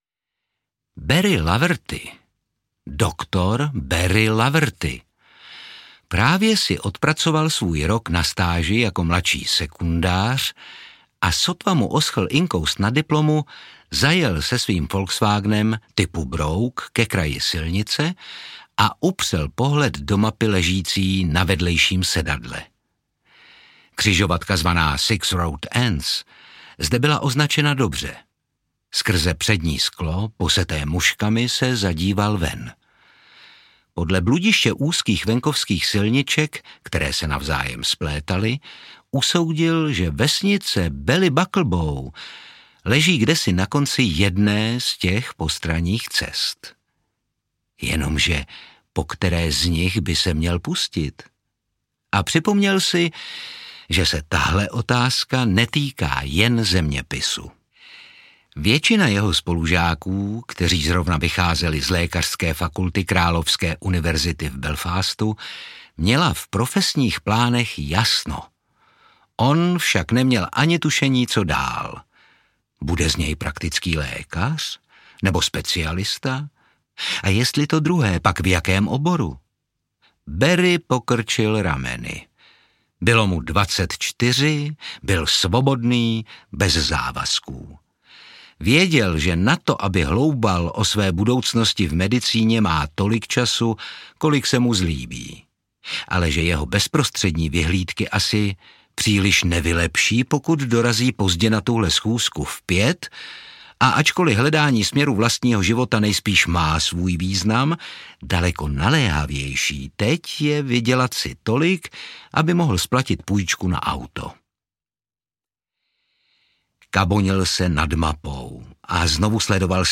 Doktore, fofrem, jde o život! audiokniha
Ukázka z knihy
| Čte Otakar Brousek.
| Vyrobilo studio Soundguru.
• InterpretOtakar Brousek ml.